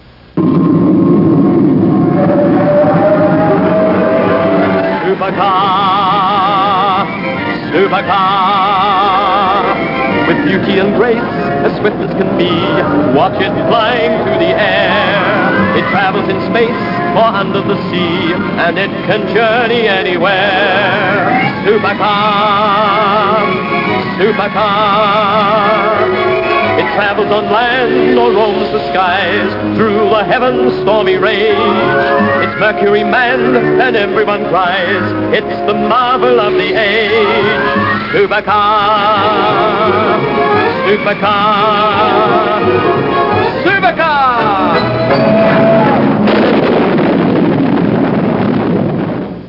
intro theme